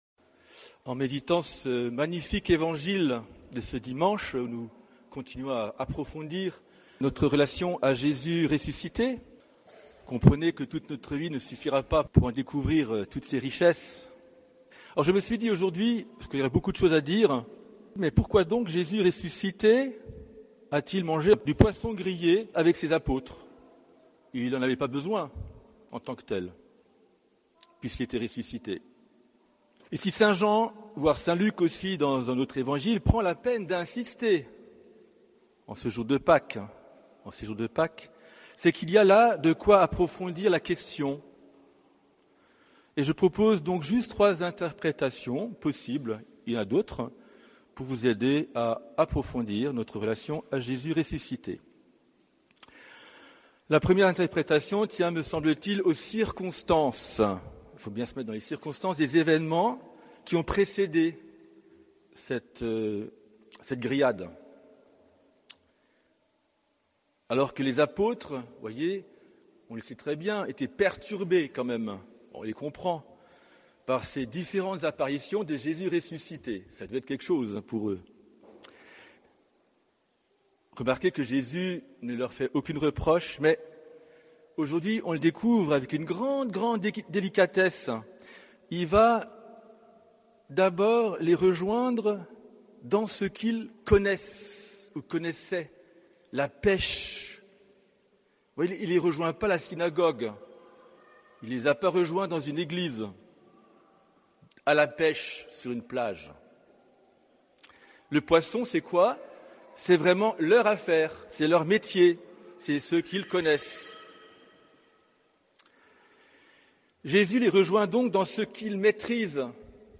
c_3e_paques_pc_2025.mp3